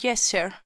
summoner_ack9.wav